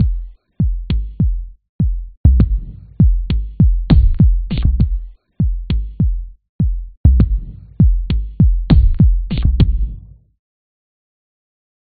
low beat0
描述：Low freq drums beat
标签： beat loops electronic drums
声道立体声